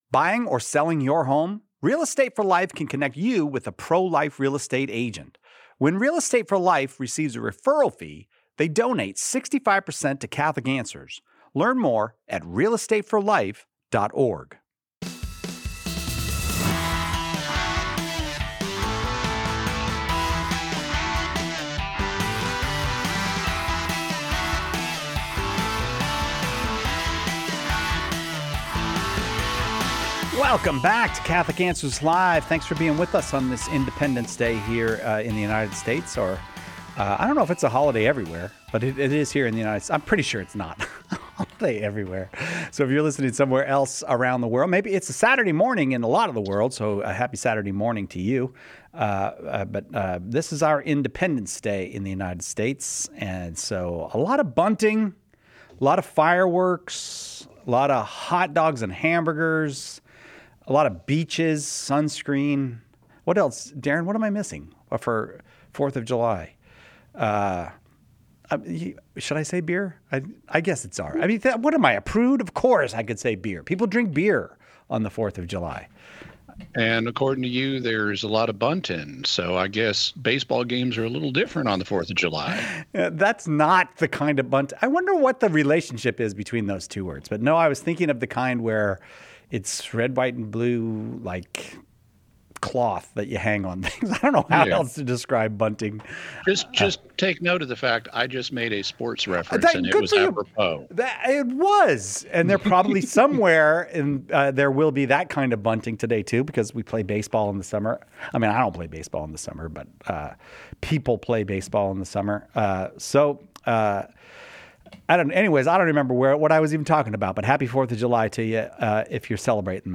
Whether you’re exploring Catholic teachings or deepening your faith, this Q&A offers clear, faithful answers rooted in Church teaching.